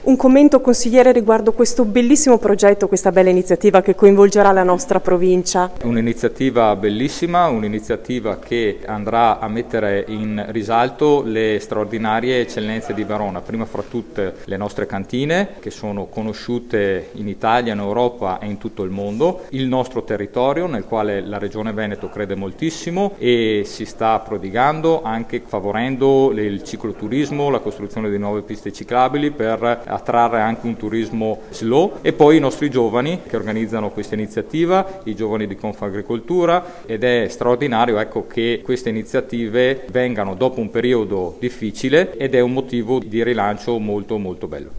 ha raccolto per noi le dichiarazioni dei presenti, per capire meglio lo spirito dell’evento.
Filippo Rigo, Consigliere della Regione del Veneto: